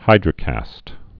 (hīdrə-kăst)